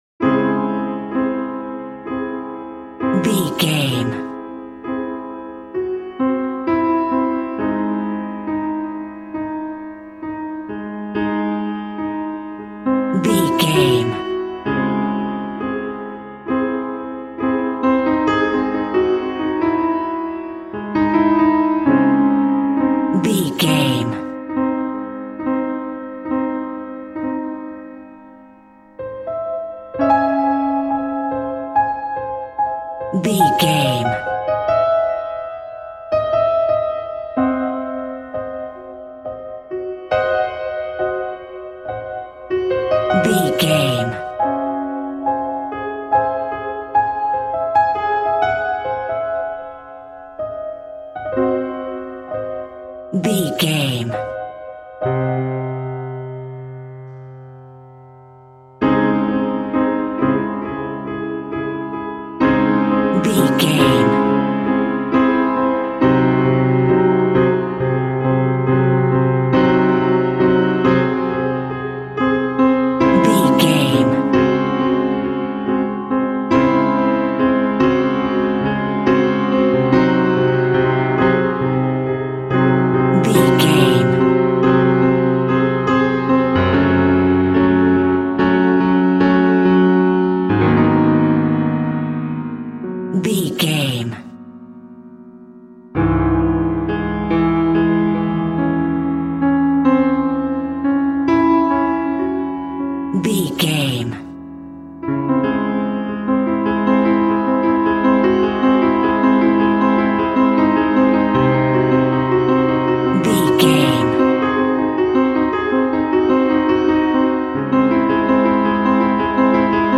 Torture Horror Music.
Aeolian/Minor
A♭
tension
ominous
dark
suspense
haunting
eerie
piano
synth
keyboards
ambience
pads
eletronic